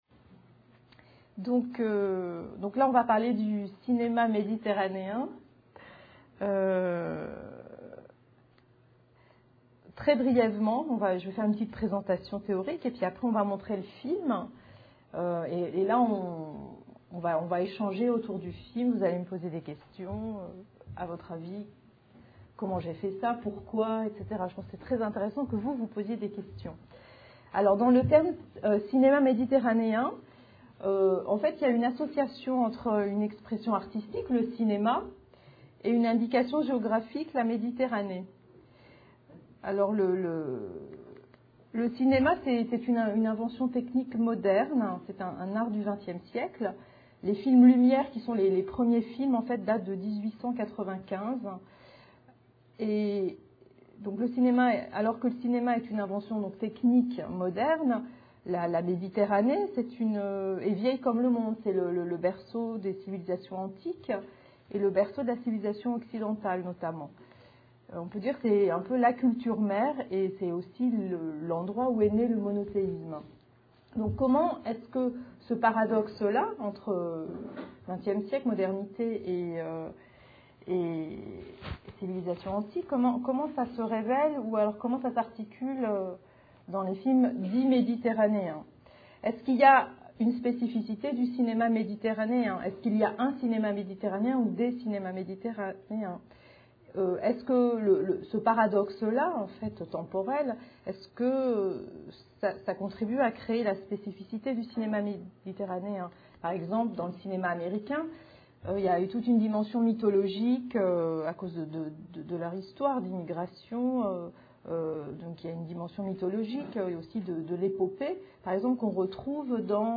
Une conférence
Lycée Pierre et Marie Curie (06 Menton)